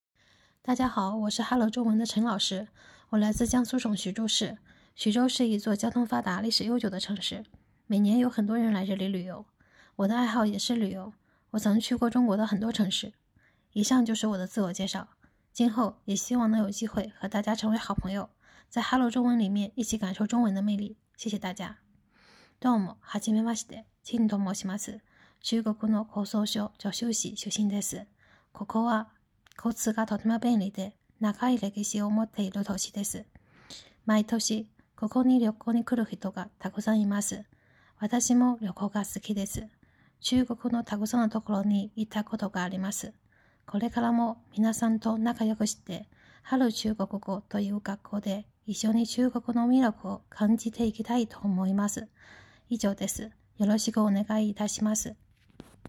音声の自己紹介